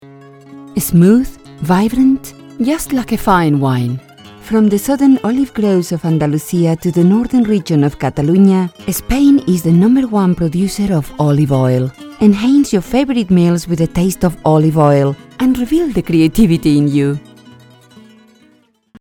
Spanish, Castilian, Neutral. Actress, warm, experienced, authority.